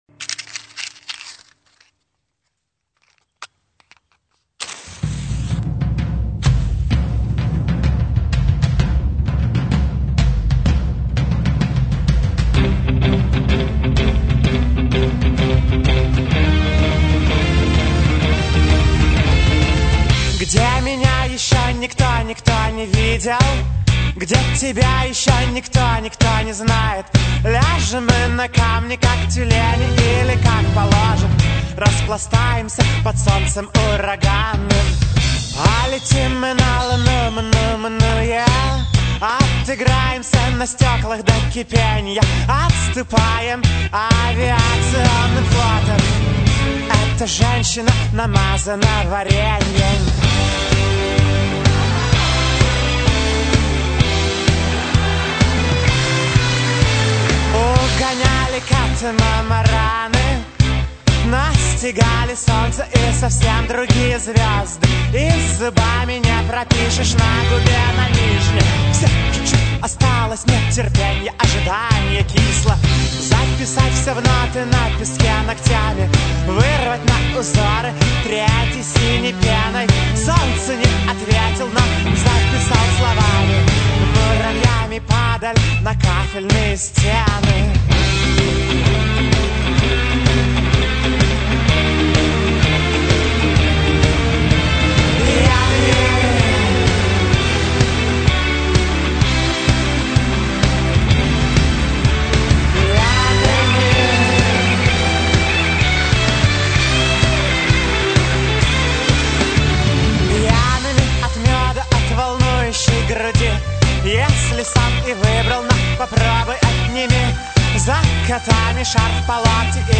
- вокал,  клавишные
- бас,  клавишные
- ударные,  программирование
- гитары,  клавишные